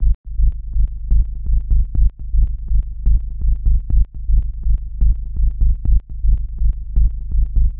• tech house bass samples - G#m - 123.wav
tech_house_bass_samples_-_G_sharp_m_-_123_nmG.wav